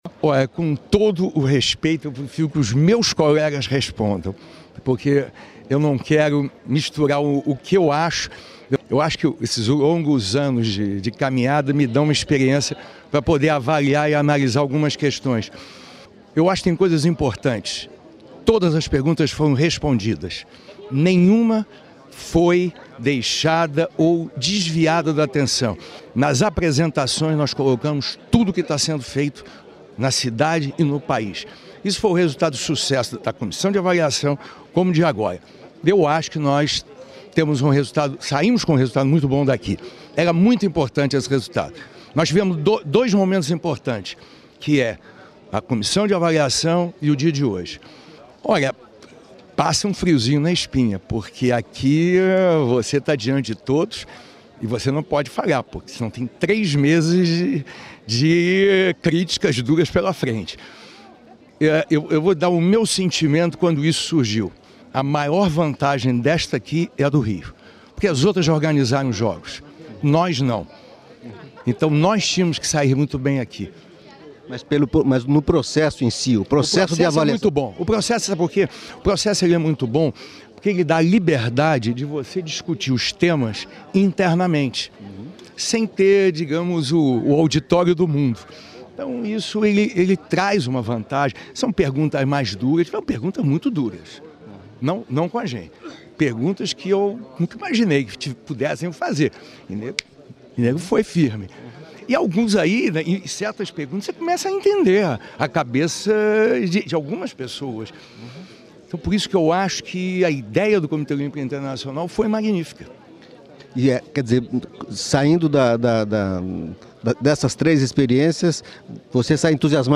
Carlos Arthur Nuzman, presidente do Comitê Olímpico Brasileiro e membro da Comissão Executiva do COI, fala do novo procedimento e da apresentação da candidatura do Rio aos JO de 2016.